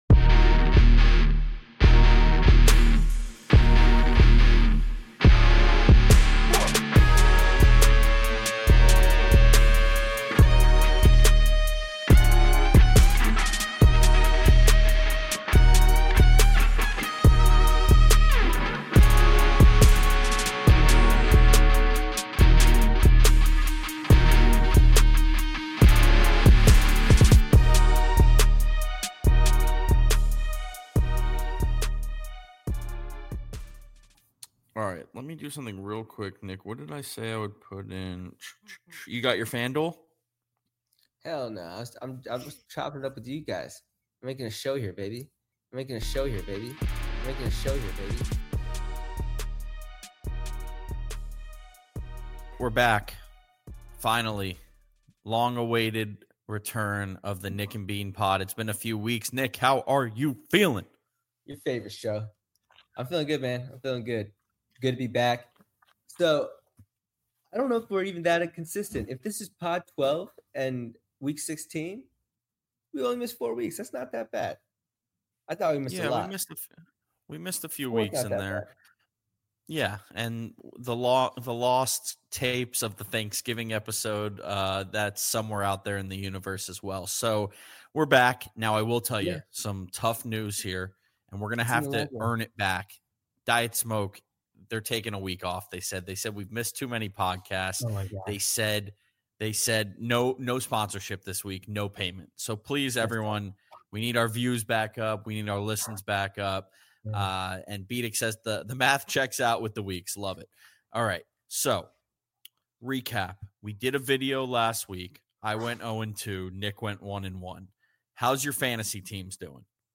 Two longtime friends talking everything sports. Gambling, fantasy football, and everything in between.